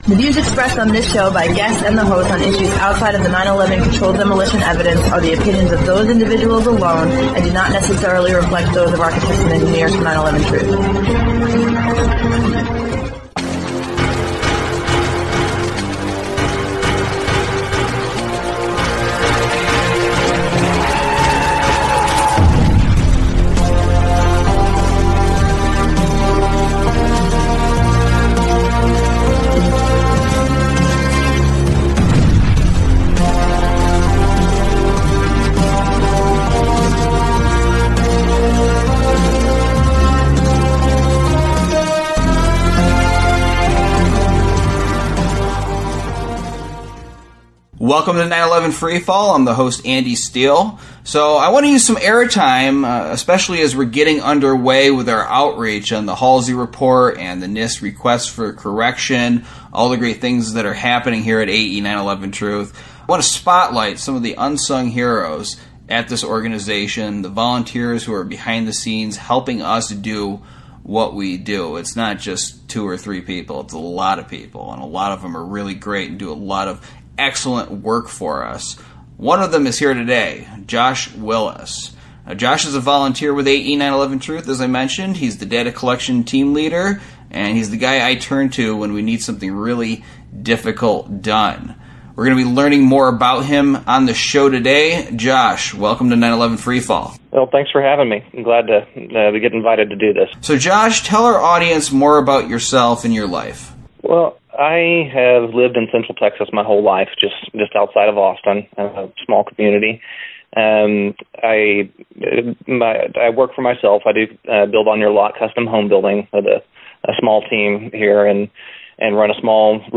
Each episode, we interview one of the many researchers and activists who are blowing the lid off the crime of the century.
Talk Show